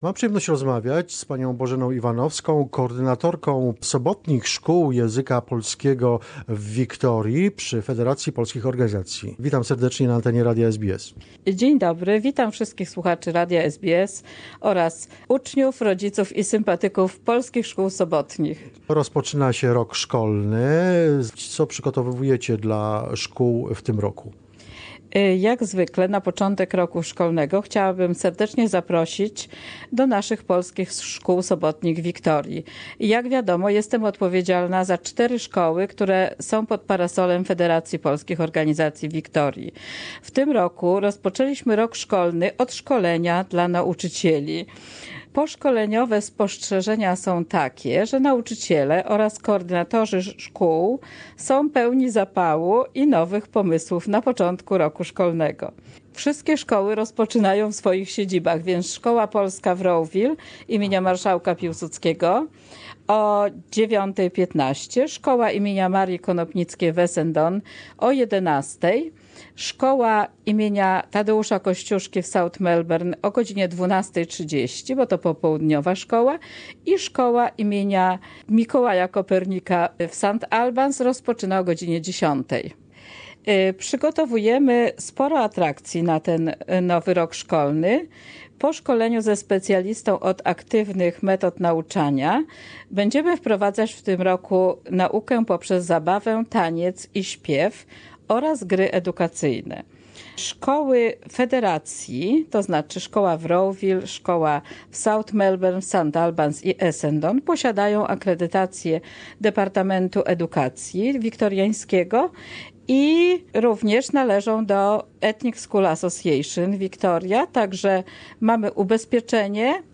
Interview with the coordinators of the Polish language schools from Polish Community Council of Victoria